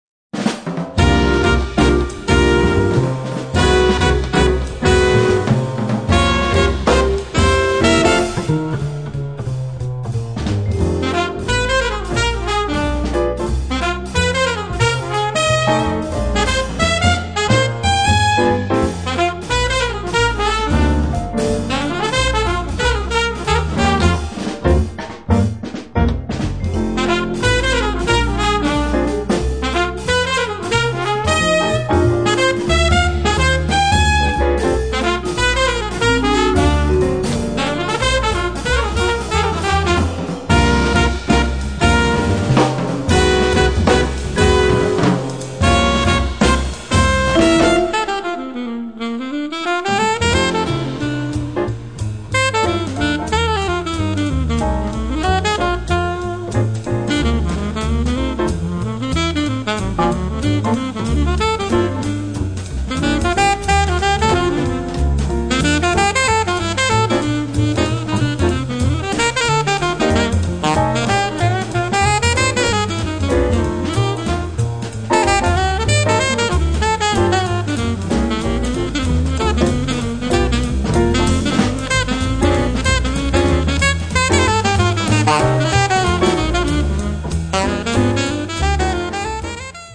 tromba, flicorno
sax alto, clarinetto
blues monkiano